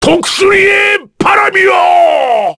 Kaulah-Vox_Skill3_kr.wav